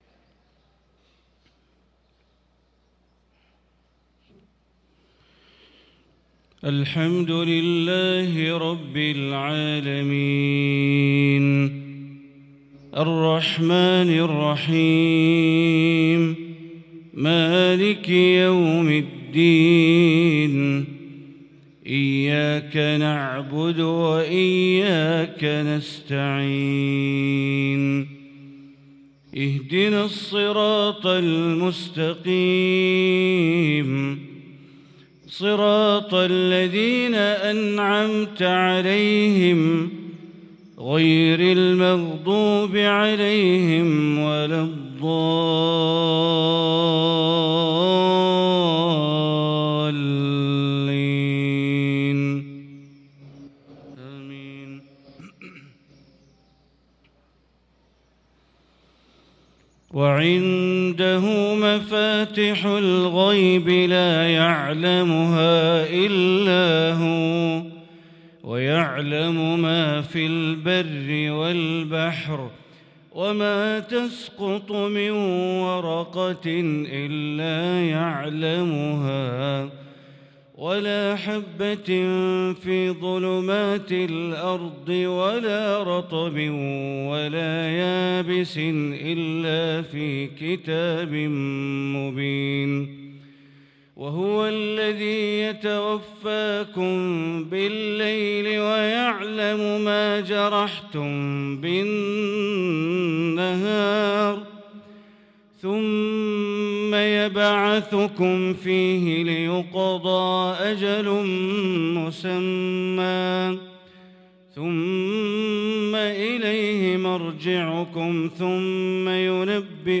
صلاة الفجر للقارئ بندر بليلة 8 شعبان 1445 هـ